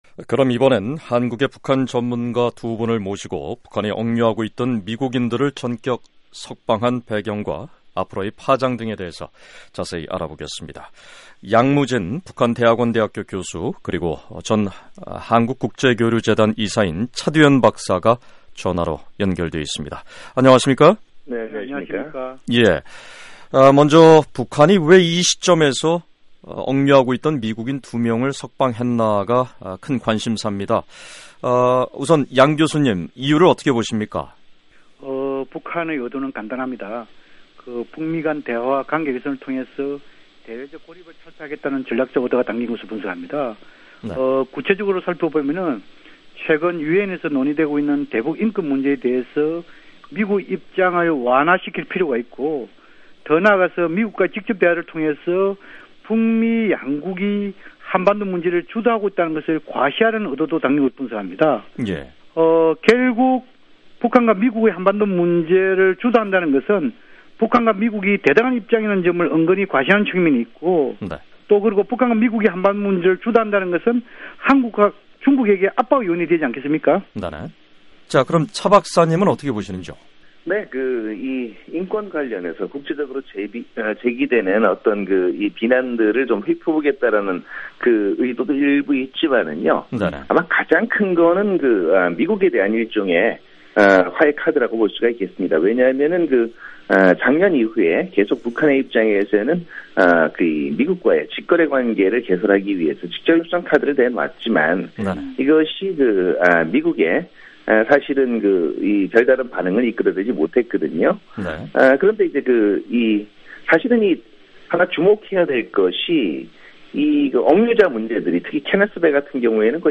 [전문가 인터뷰] 북한, 억류 미국인 전격 석방 배경과 파장